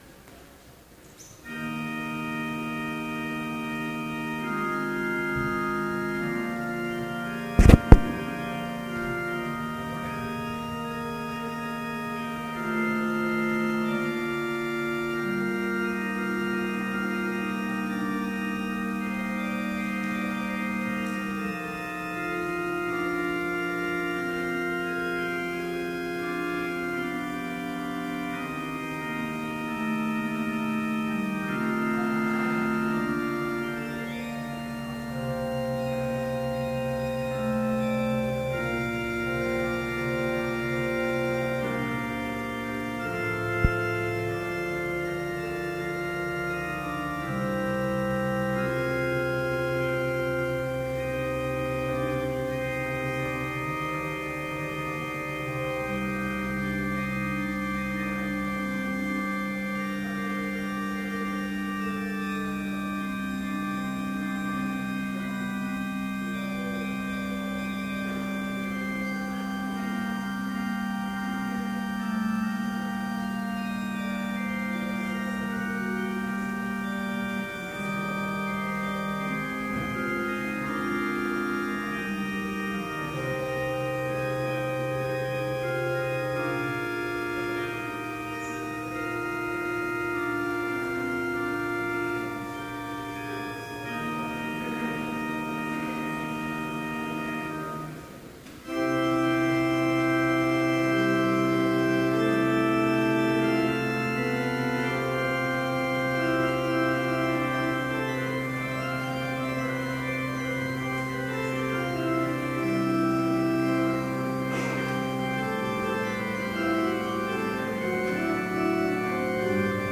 Complete service audio for Chapel - March 5, 2013